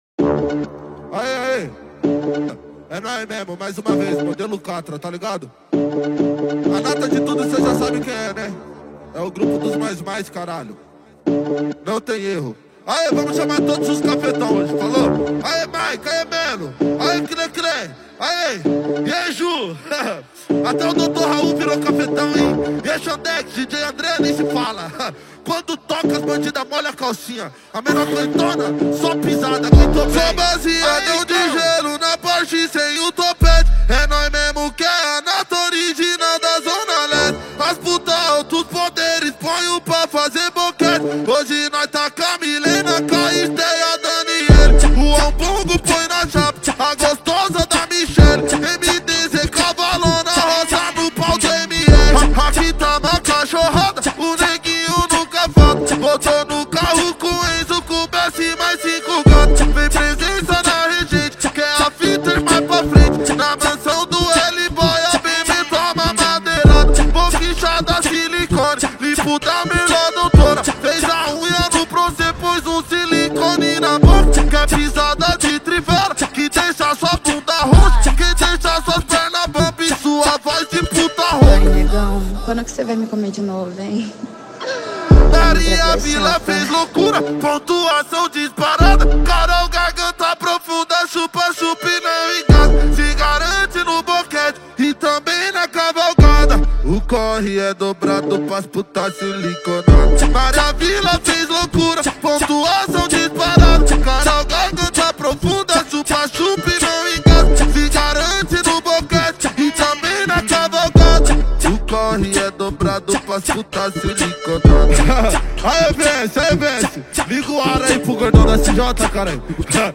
2024-11-06 23:34:21 Gênero: MPB Views